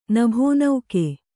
♪ nabhō nauke